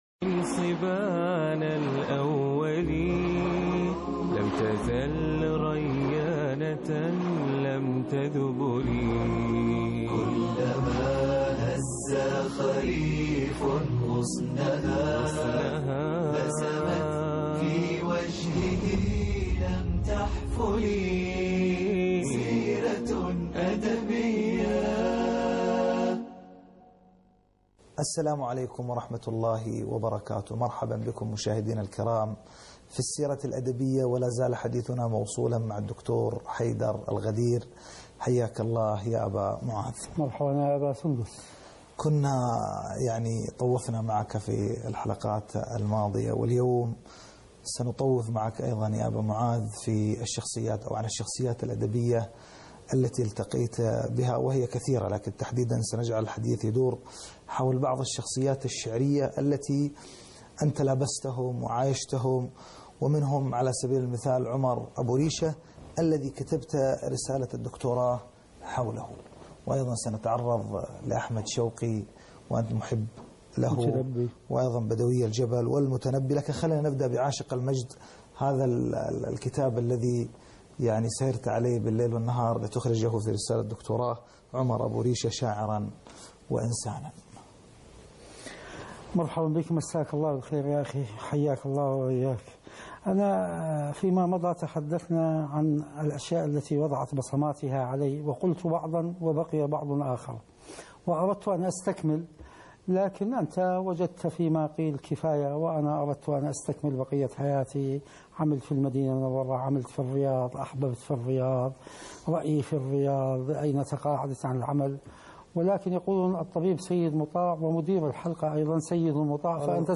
لقاء